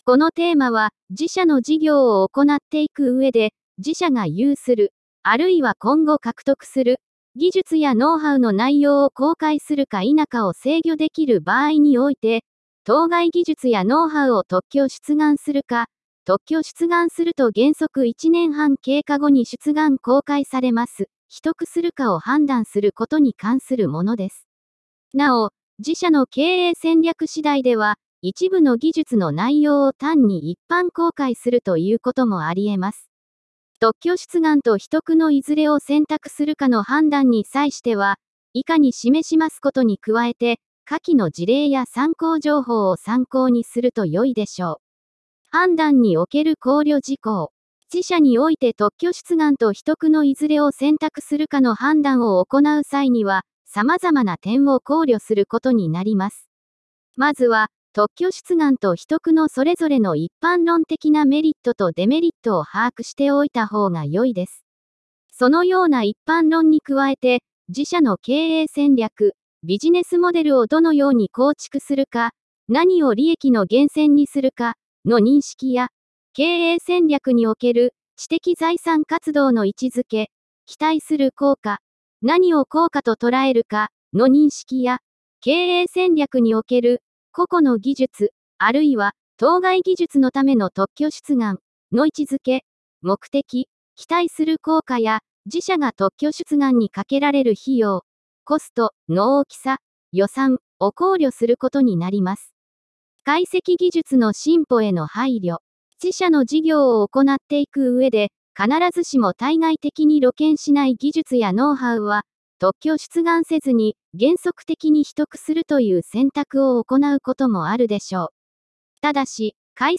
テーマの説明音声データ＞＞